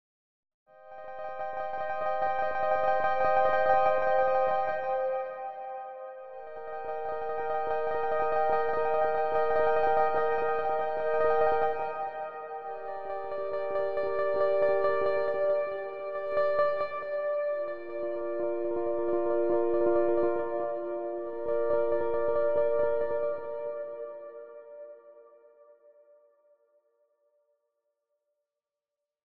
I didn’t get the zipper sound
Slow attack allows for striking dynamically and then ramping up to that volume as you press harder.
I also looped a section of the sample to better show what aftertouch is doing.